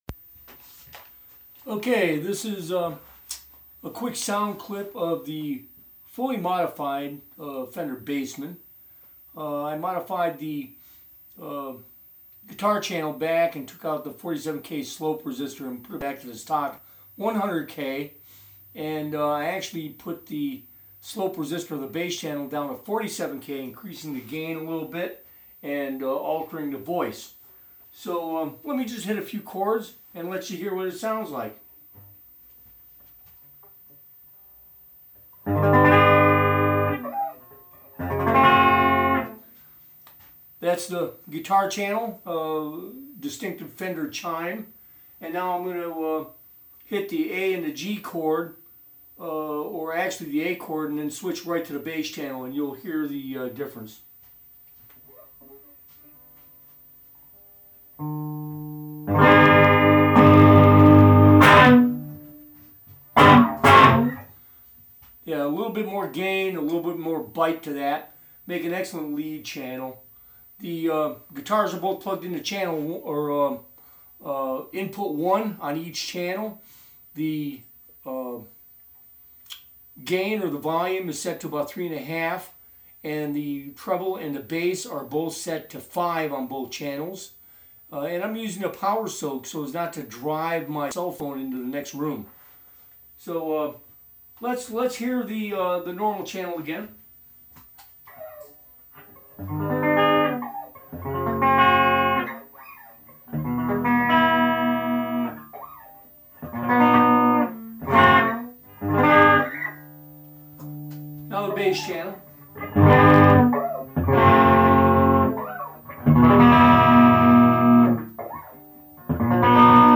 From the last segment, this is with the slope resistor changed to 47K, and the hi-cut cap removed from the circuit.
Bass and Guitar Channel 47K Ohm bass slope A/B